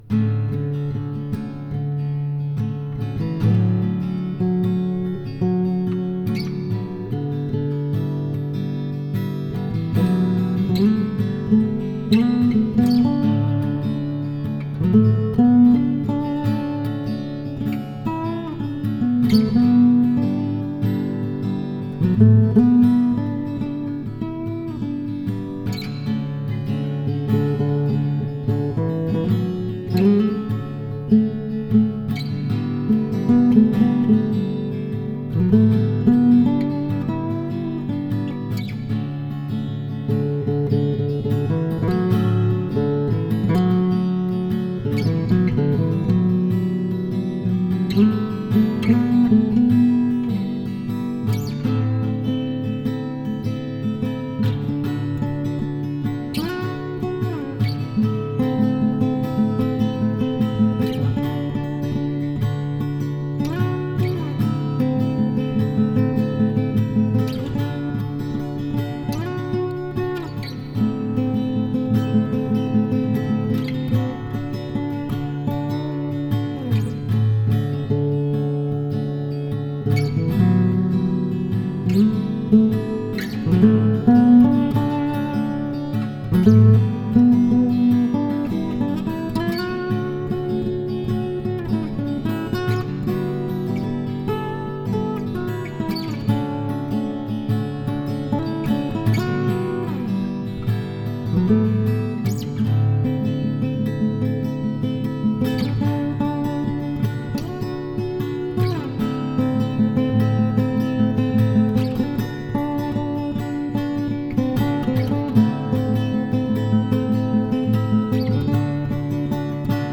Tempo: 75 bpm / Datum: 08.12.2015